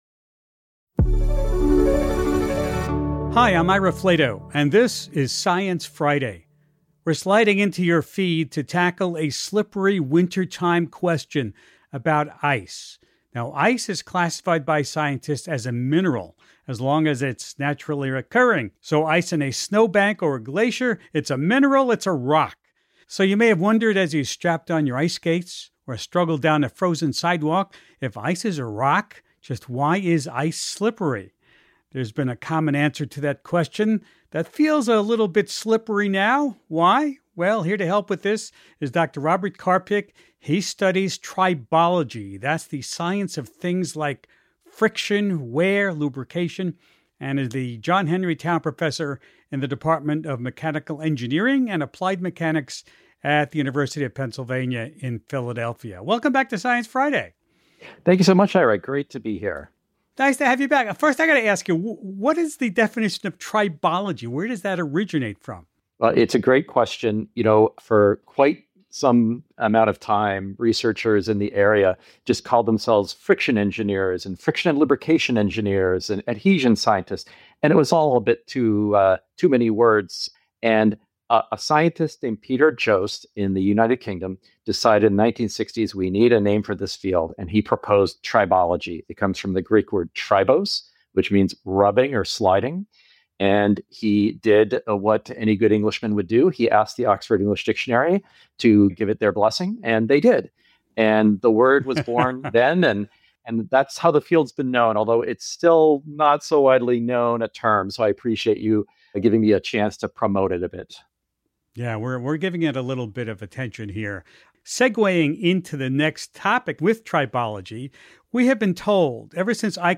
He joins Host Ira Flatow to wrangle some new ideas about the slippery science of ice.